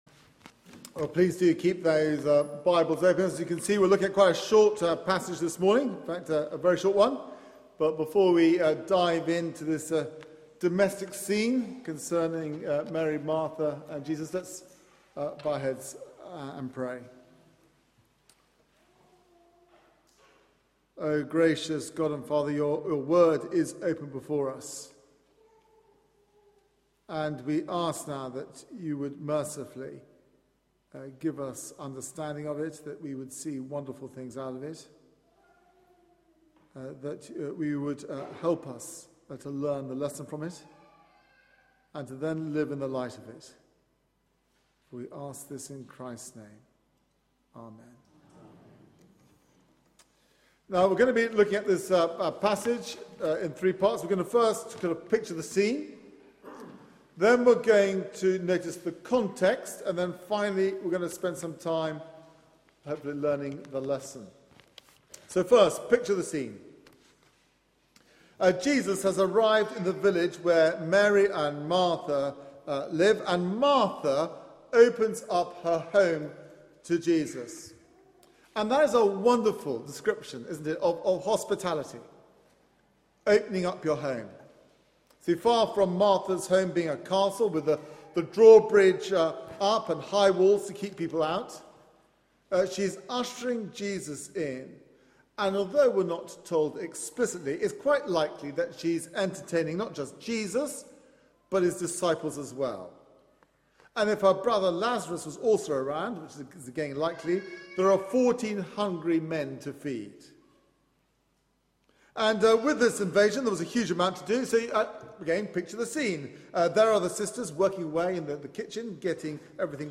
Media for 9:15am Service on Sun 11th Aug 2013
Sermon